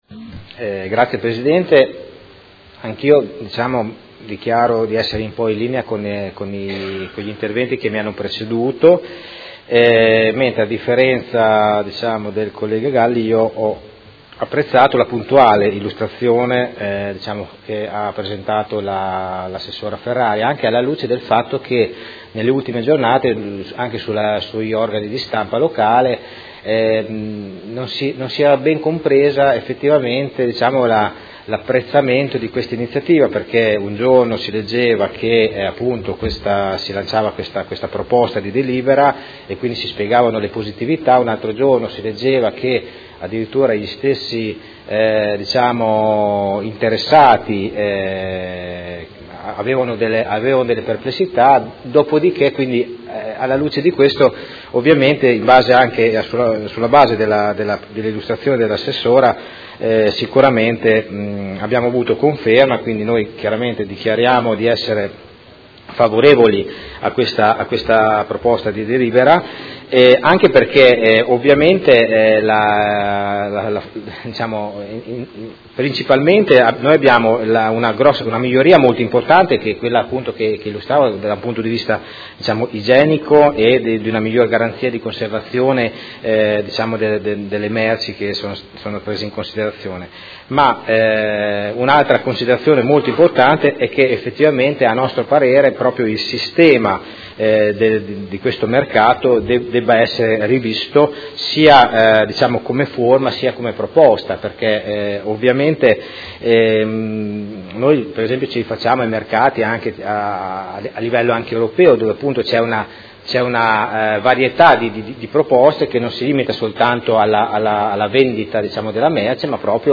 Seduta del 28/03/2019. Dichiarazione di voto su proposta di deliberazione: Regolamento comunale del Mercato quotidiano di generi alimentari denominato “Mercato Albinelli” – Approvazione